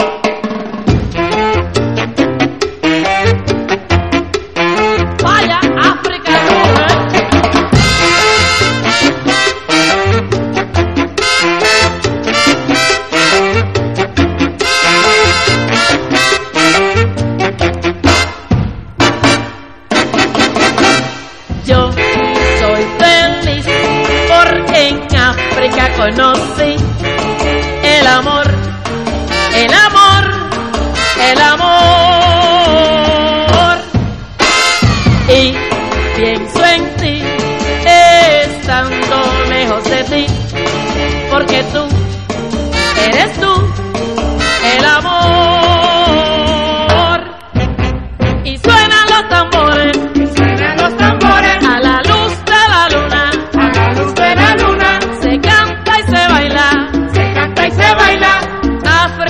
WORLD / LATIN / PACHANGA / CHARANGA / CHA CHA CHA / MAMBO
全曲楽しい61年チャランガ～パチャンガ・グルーヴ！